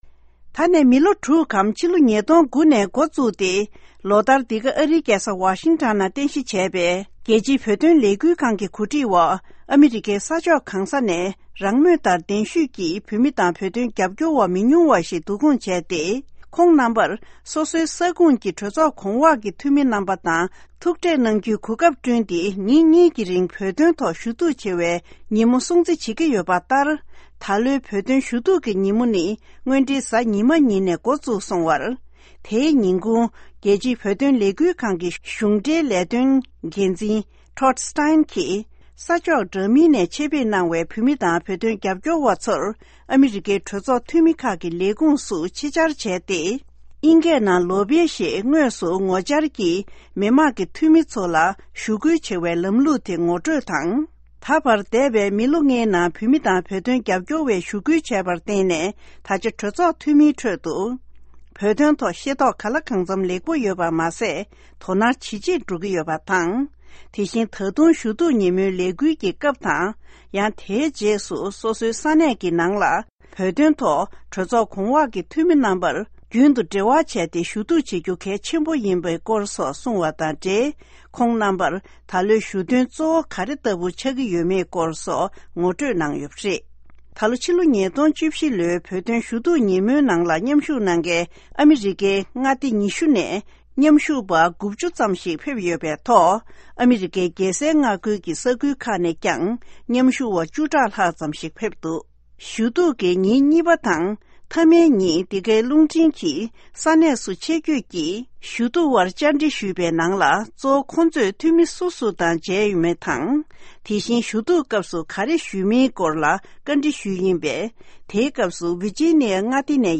གྲོས་ཚོགས་ཁང་གི་སར་བསྐྱོད་དེ་ཞུ་གཏུགས་རྒྱག་མཁན་དང་འབྲེལ་བ་བྱས་ཏེ་ཕྱོགས་བསྒྲིགས་གནང་བའི་གནས་ཚུལ་དེ་གསན་གྱི་རེད།